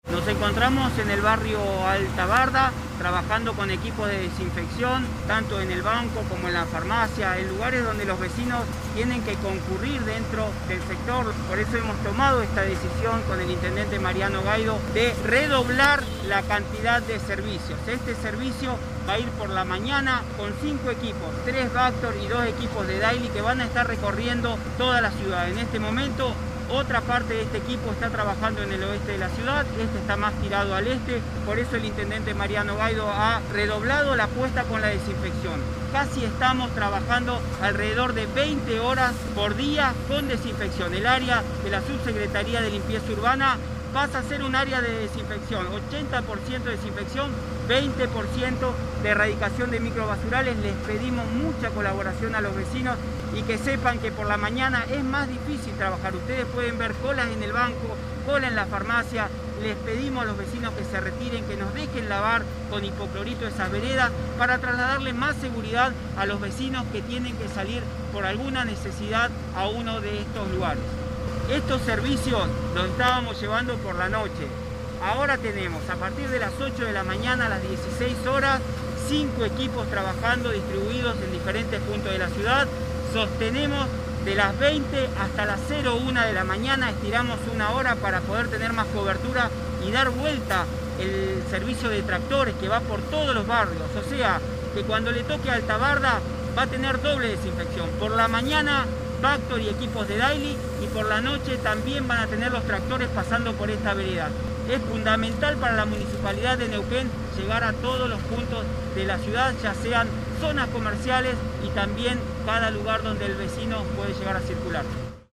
Cristian Haspert, subsecretario de Limpieza Urbana.
Haspert-EDITADO-Desinfeccion-en-Alta-Barda.mp3